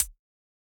Index of /musicradar/ultimate-hihat-samples/Hits/ElectroHat A
UHH_ElectroHatA_Hit-12.wav